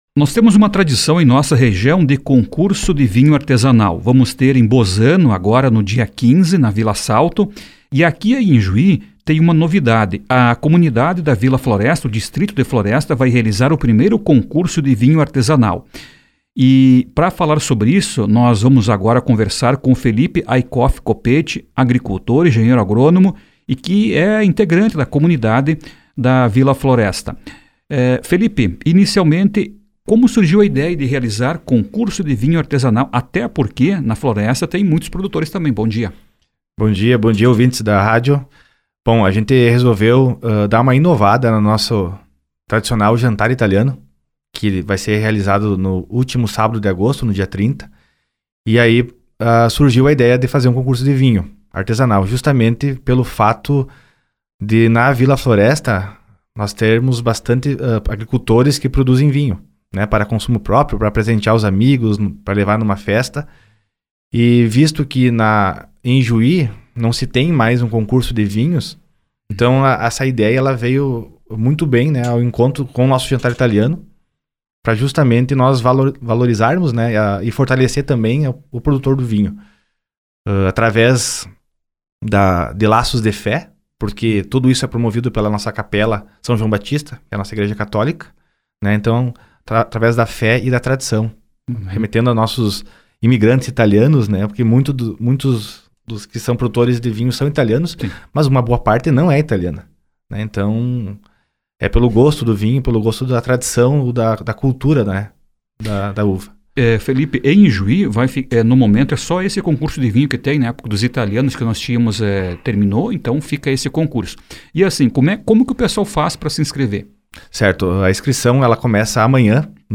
A entrevista ontem, no Progresso Rural, ainda detalhou a premiação.